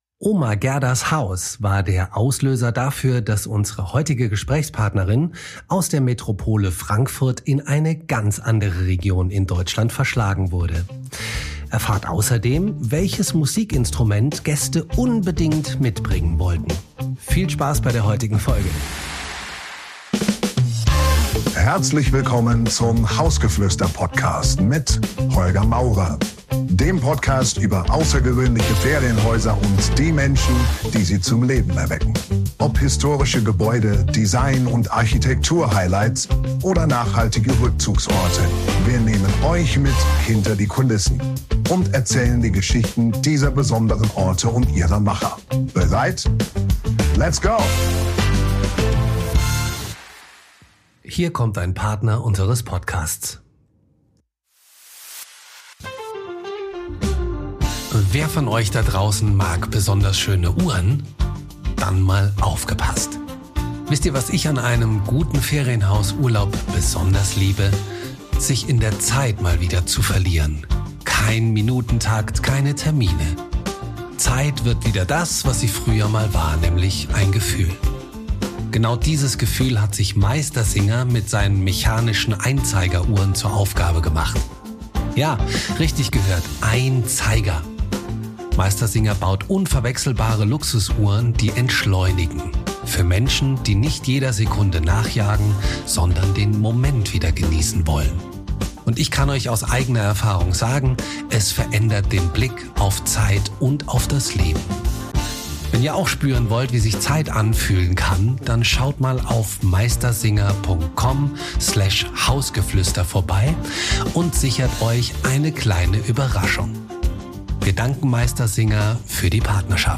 Ein Gespräch über das Glück im Kleinen, die Kraft von Orten – und darüber, warum manchmal genau dort Magie entsteht, wo man sie am wenigsten erwartet.